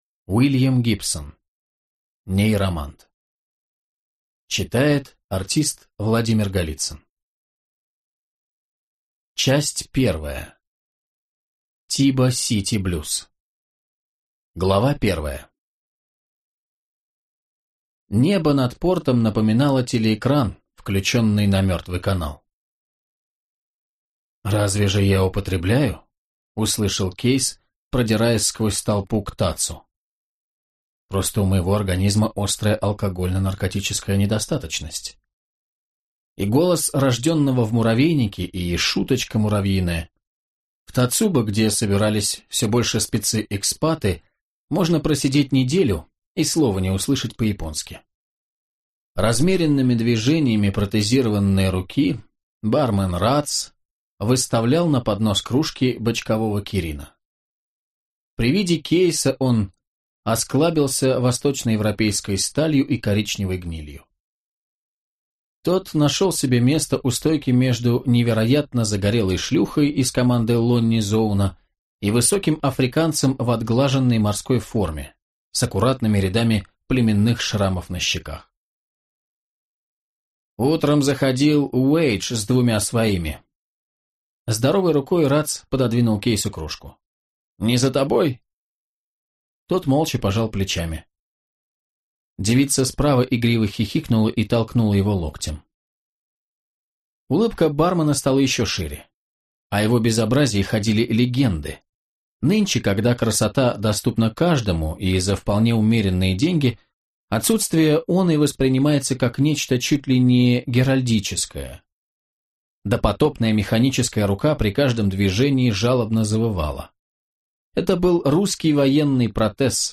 Аудиокнига Нейромант | Библиотека аудиокниг
Прослушать и бесплатно скачать фрагмент аудиокниги